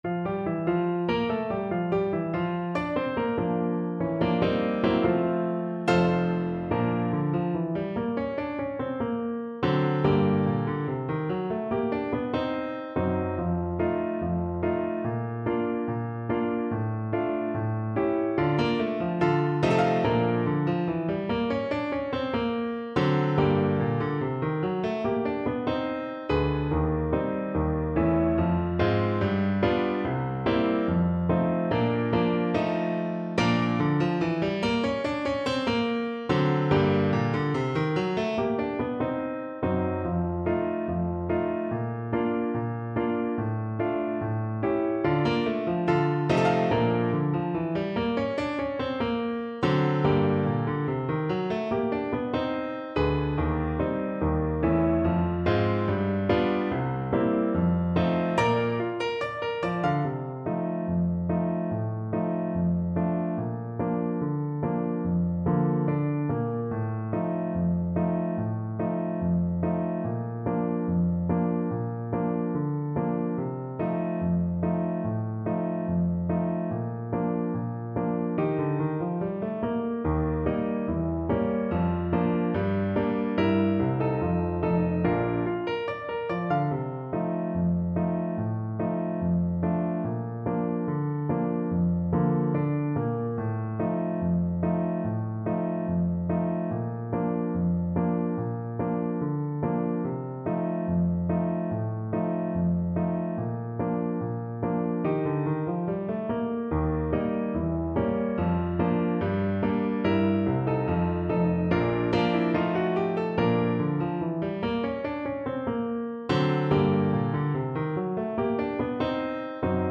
Tuba version
2/4 (View more 2/4 Music)
Slow march tempo = 72
Tuba  (View more Advanced Tuba Music)
Jazz (View more Jazz Tuba Music)